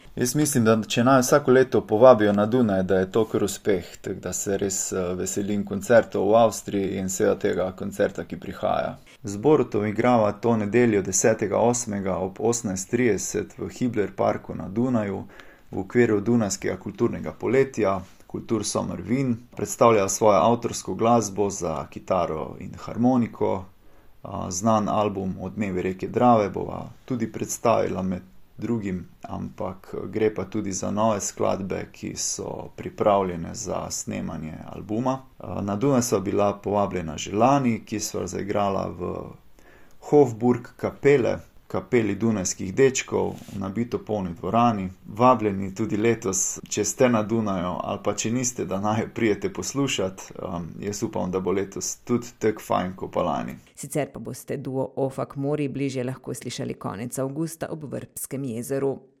Na festivalu Kultursommer predstavljata avtorske skladbe